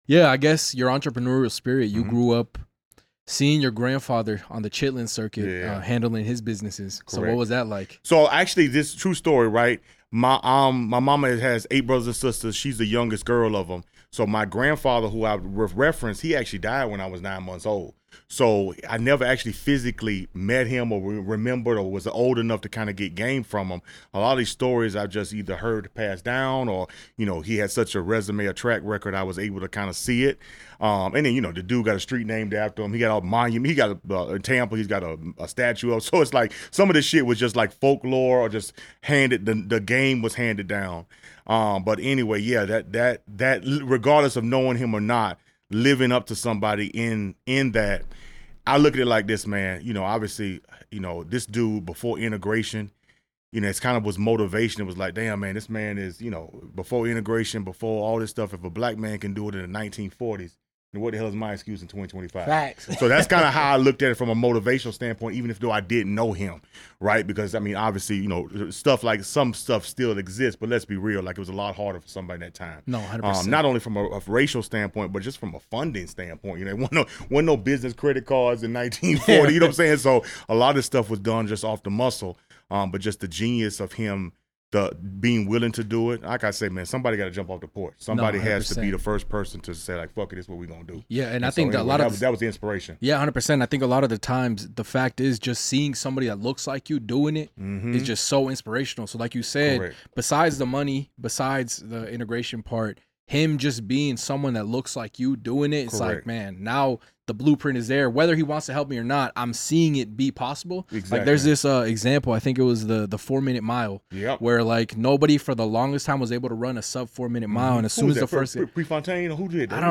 This is a business podcast for real ones: founders, creatives, and builders who want the behind-the-scenes game.